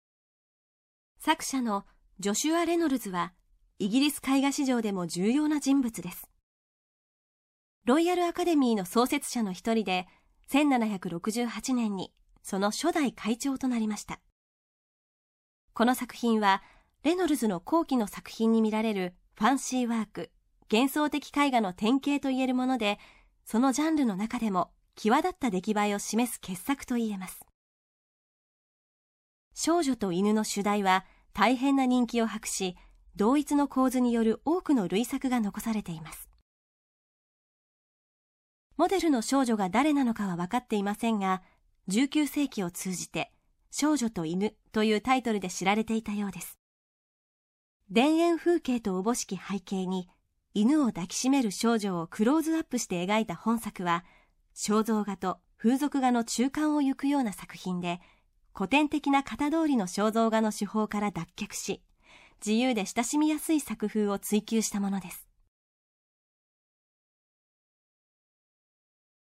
作品詳細の音声ガイドは、すべて東京富士美術館の公式ナビゲーターである、本名陽子さんに勤めていただいております。本名さんは声優、女優、歌手として幅広く活躍されています。